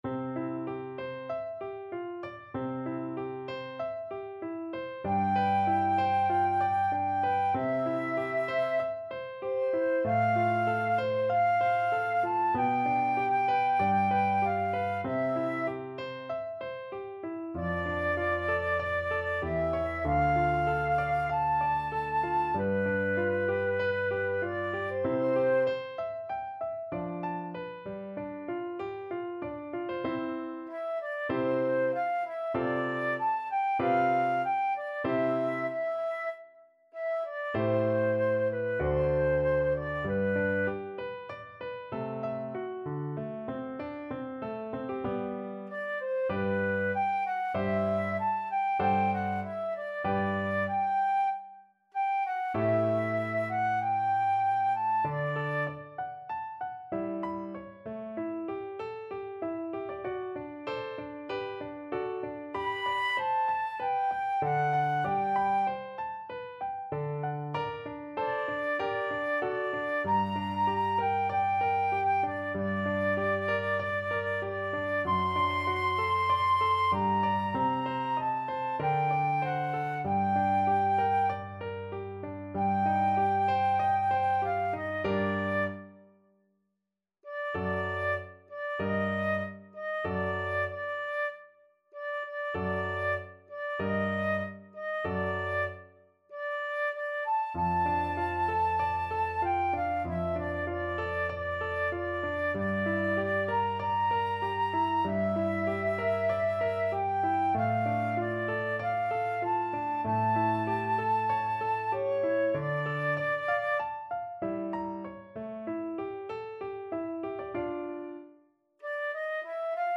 Flute version
~ = 96 Andante
Flute  (View more Easy Flute Music)
Classical (View more Classical Flute Music)